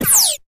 Synth Zaps
Synth Energy Zap, Quick High Pitched